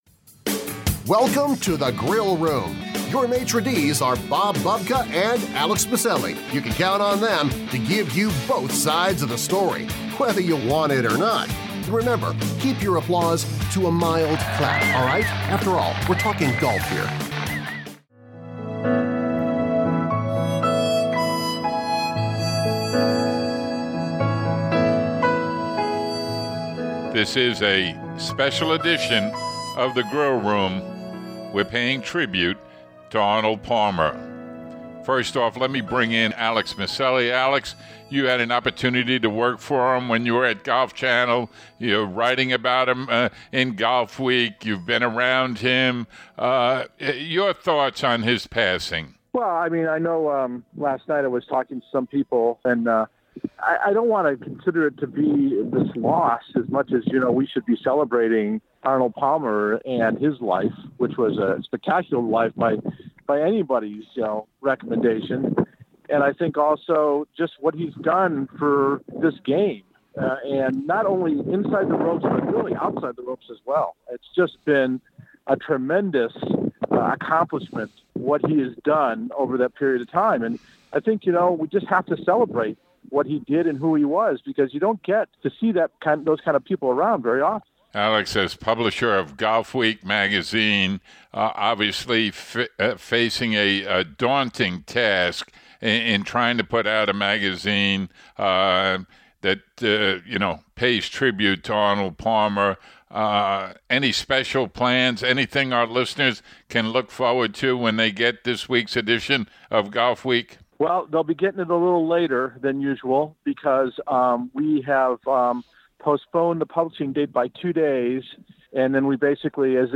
From a previous interview, Arnie shares his method for handling pressure in the majors - a lesson learned from his father.